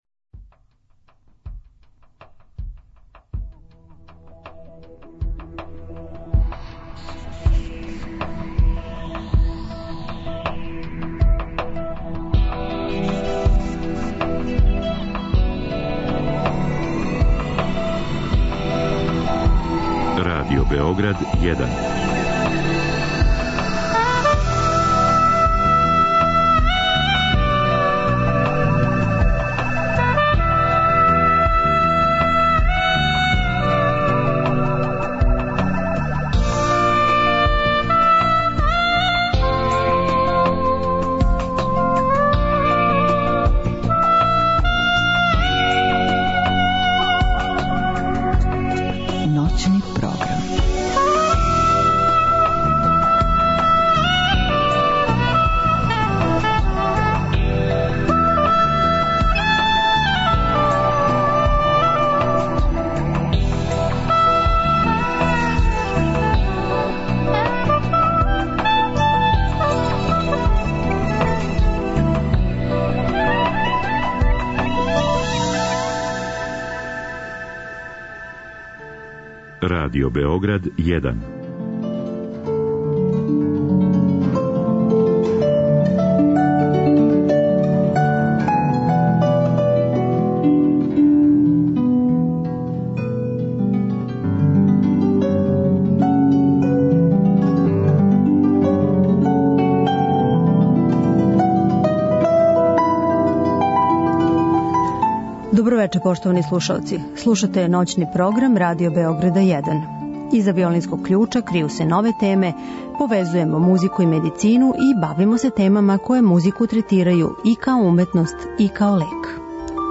Бавимо се покретом и његовим могућностима у побољшању здравља. Гошћа у студију